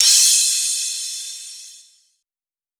VTDS2 Song Kits 128 BPM Pitched Your Life